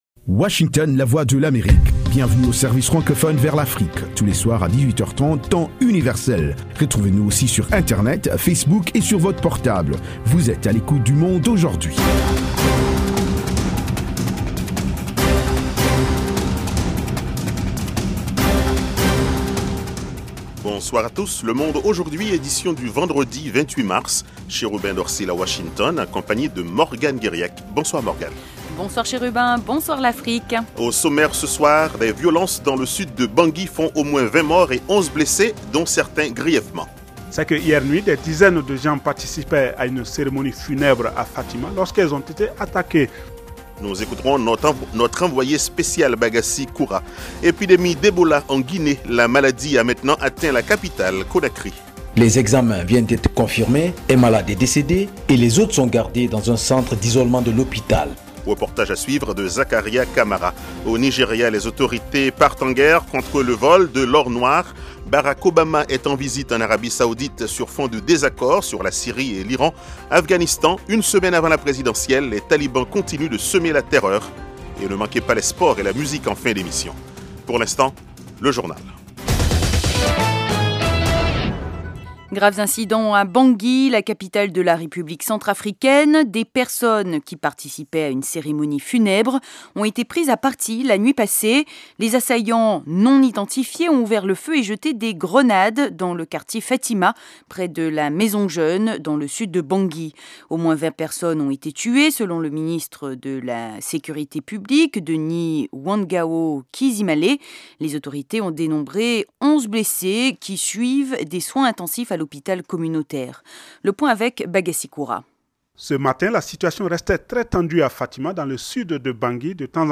Toute l’actualité sous-régionale sous la forme de reportages et d’interviews. Des dossiers sur l'Afrique etle reste du monde. Le Monde aujourd'hui, édition pour l'Afrique de l’Ouest, c'est aussi la parole aux auditeurs pour commenter à chaud les sujets qui leur tiennent à coeur.